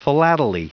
Prononciation du mot philately en anglais (fichier audio)
Prononciation du mot : philately